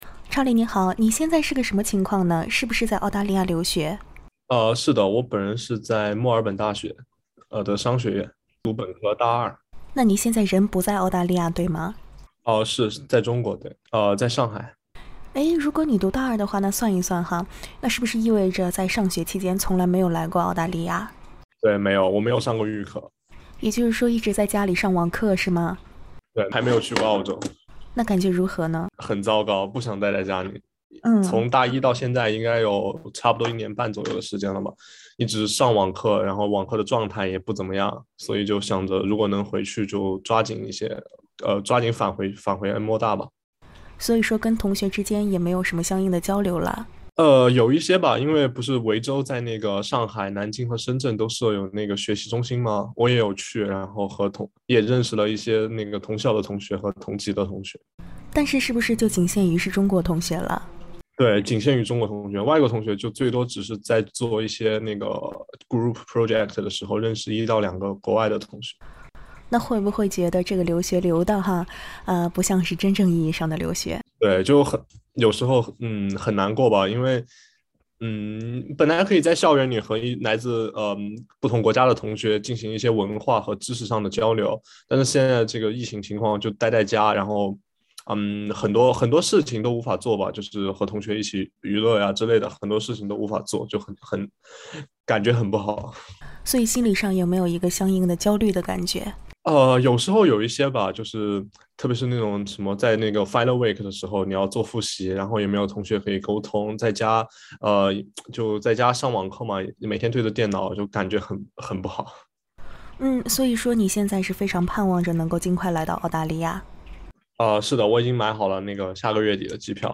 請點擊音頻收聽寀訪： LISTEN TO “为了返澳学习，我打了五针疫苗”：留学生自述 SBS Chinese 08:14 cmn （本文系SBS中文普通話節目原創內容，未經許可，不得轉載。